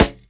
Snare
Original creative-commons licensed sounds for DJ's and music producers, recorded with high quality studio microphones.
dry-acoustic-snare-sample-g-sharp-key-107-0l7.wav